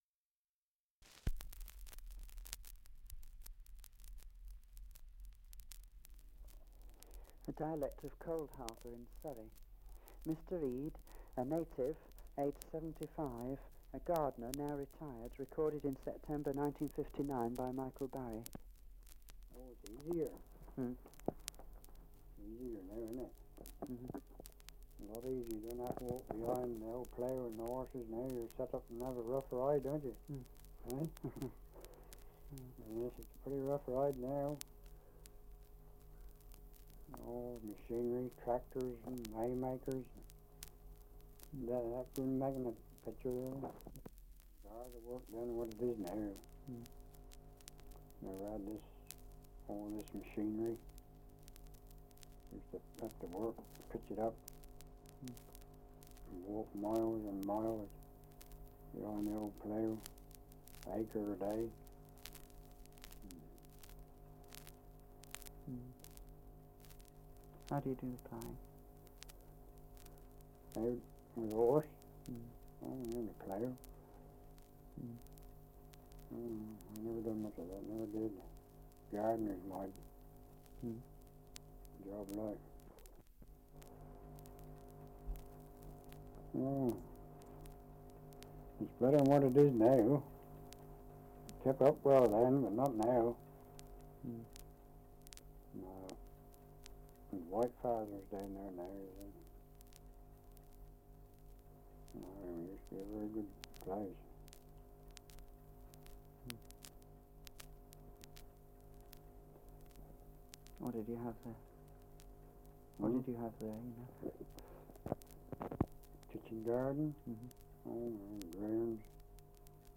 Survey of English Dialects recording in Coldharbour, Surrey
78 r.p.m., cellulose nitrate on aluminium